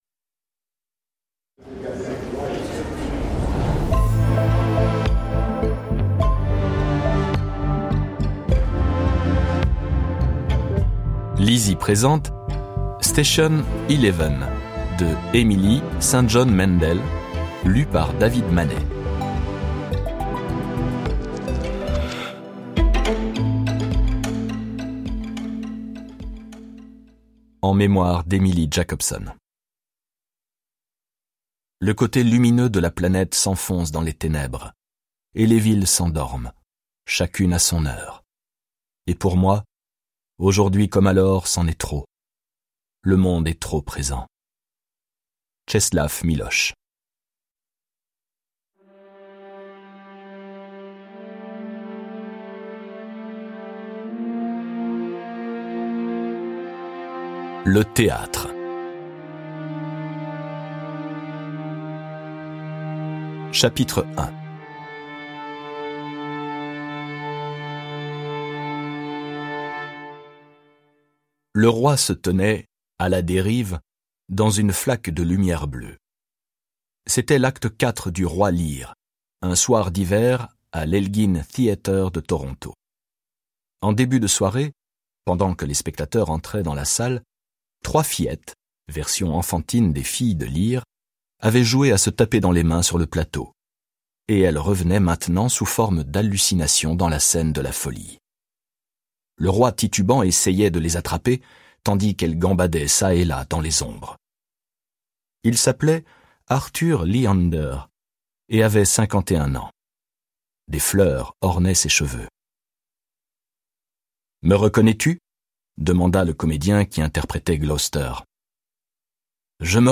Extrait gratuit - Station Eleven de Emily St. john mandel